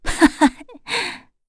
FreyB-Vox_Happy1.wav